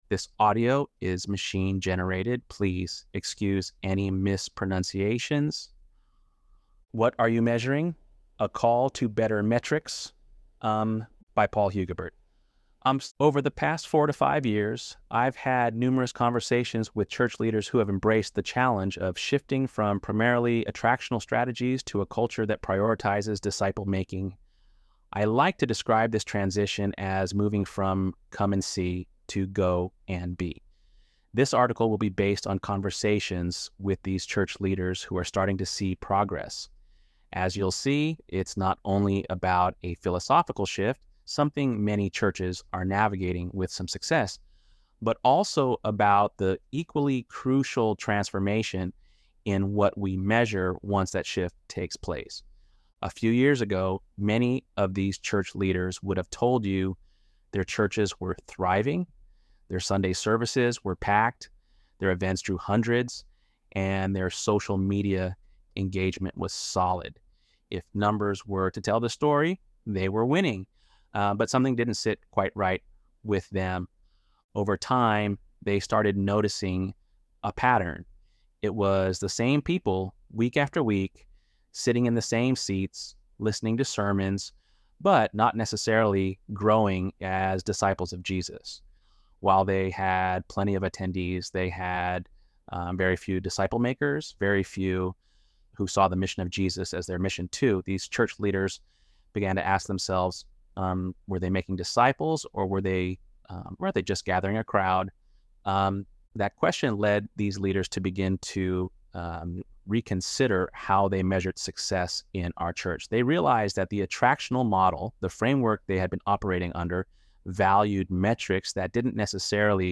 ElevenLabs_4.26_Metrics.mp3